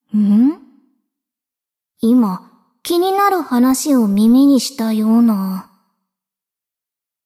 BA_V_Kotama_Camping_Cafe_monolog_5.ogg